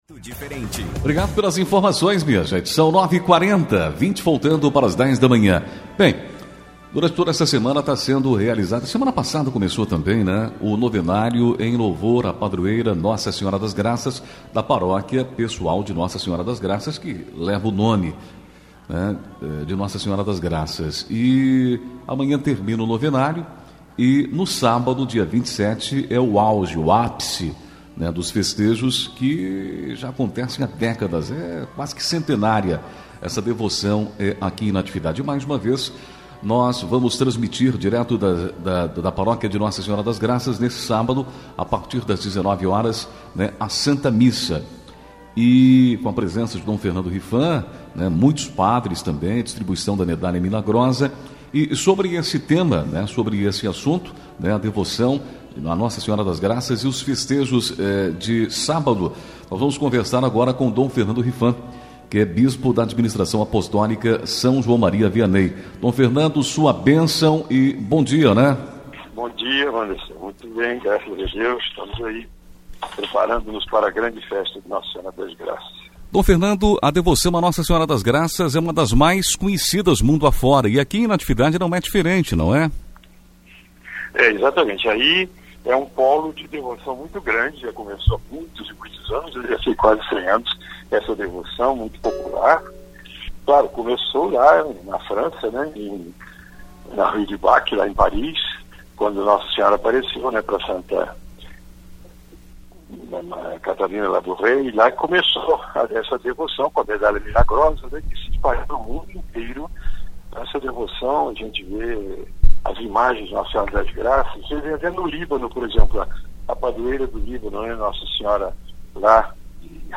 D. Fernando Rifan fala à Rádio Natividade sobre a festa de N.S das Graças – OUÇA
25 novembro, 2021 ENTREVISTAS, NATIVIDADE AGORA